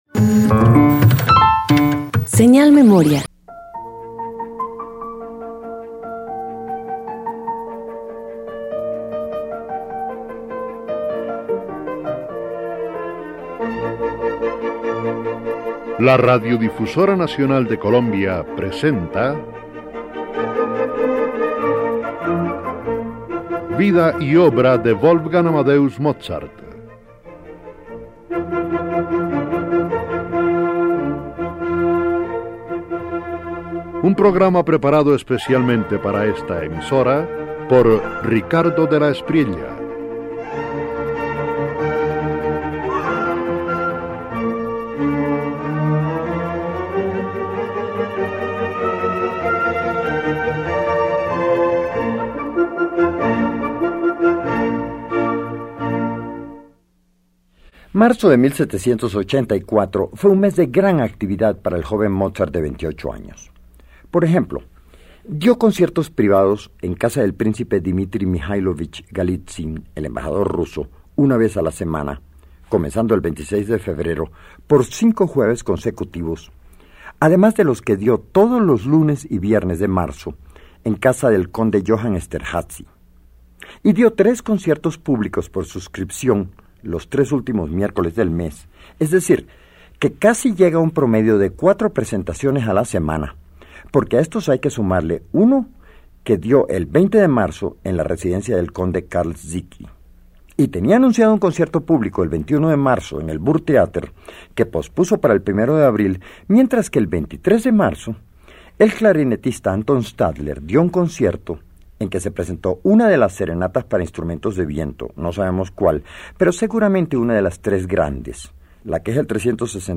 Concierto para piano y orquesta